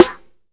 SNARE.2.NEPT.wav